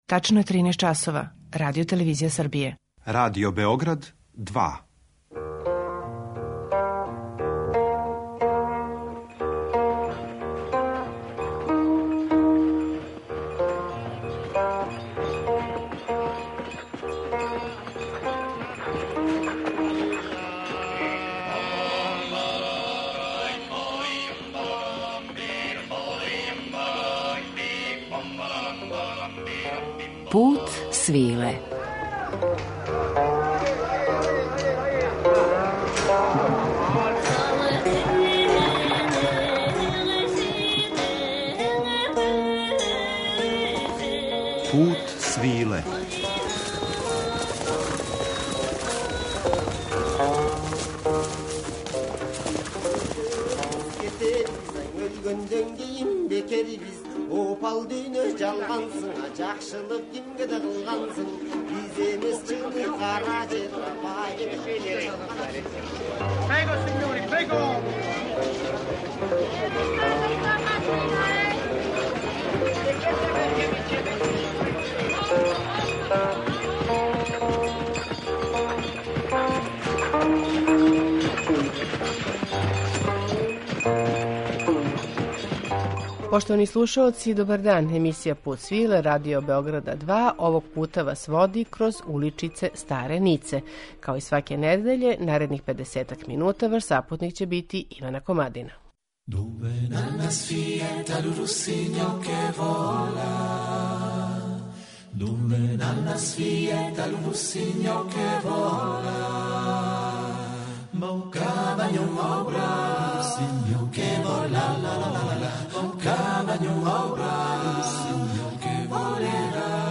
У данашњем Путу свиле доказујемо зашто је стара Ница савршено место за неговање уметности доколице, уз музику ансамбла Corou de Berra, који негује традиционалну вокалну полифонију овог дела Француске.